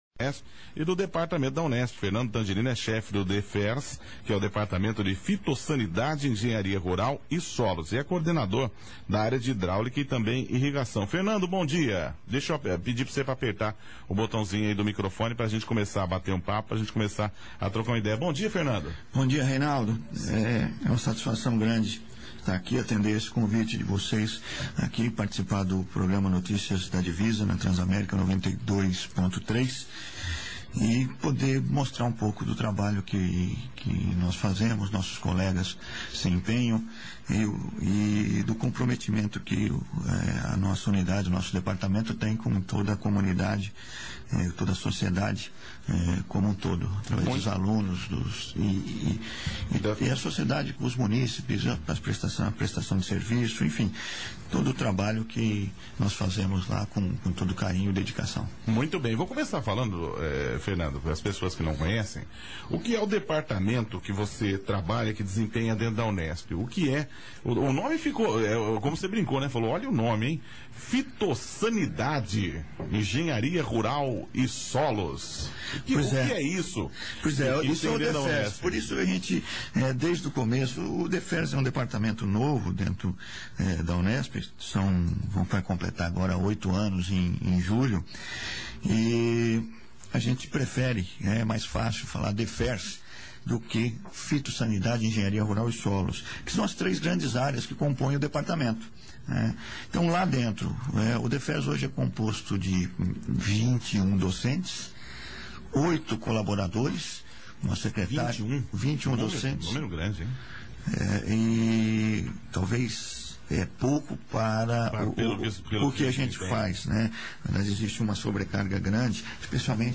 Reunião técnica sobre os recursos hídricos da microbacia do córrego do Coqueiro
A Área de Hidráulica e Irrigação da UNESP Ilha Solteira promoveu no dia 27 de agosto de 2011 no Lions Clube de Palmeira d’Oeste a Reunião técnica sobre os recursos hídricos da microbacia do córrego do Coqueiro, visando instruir os irrigantes locais a partir do diagnóstico ambiental realizado através do monitoramento sistemático da qualidade da água e da vazão e também do uso e ocupação do solo na microbacia. O trabalho permite alertar sobre o uso consciente da água, através dos sistemas de irrigação.